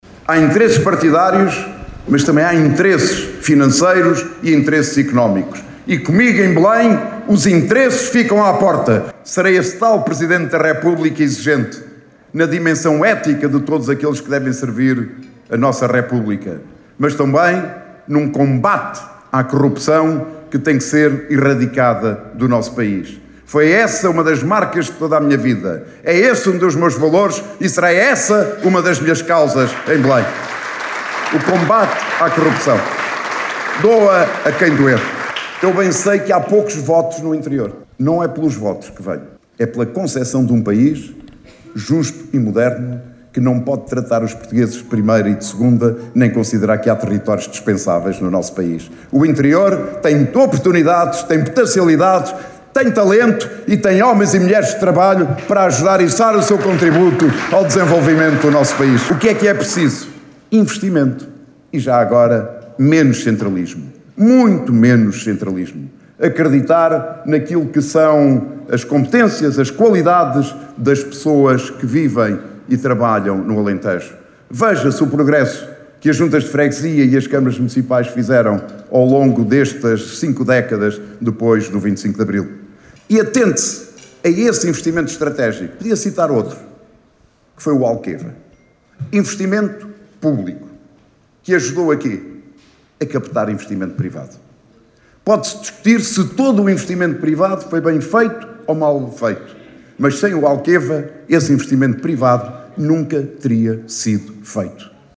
António José Seguro esteve esta segunda-feira, na Vidigueira, onde participou num almoço convívio, no Mercado Municipal.